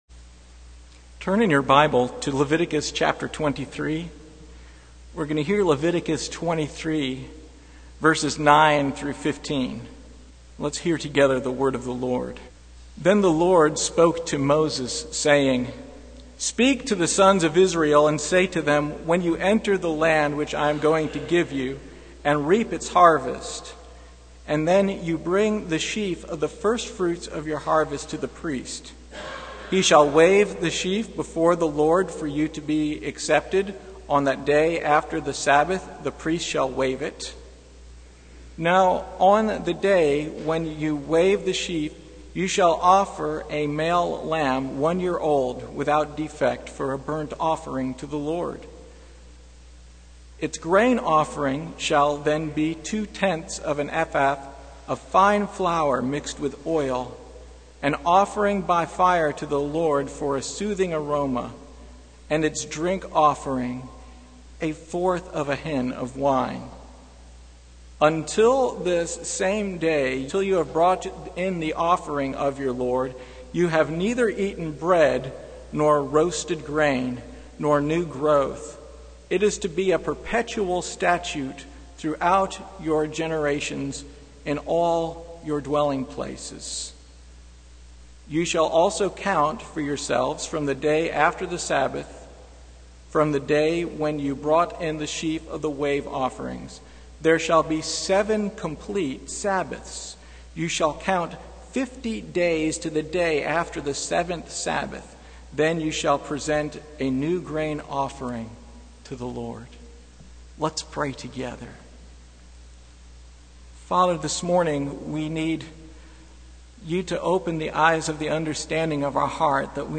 Passage: Leviticus 23:1-24:5 Service Type: Sunday Morning